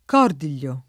cordiglio [kord&l’l’o] s. m. (eccl.); pl. -gli — difficile a spiegarsi la vecchia pn. tosc.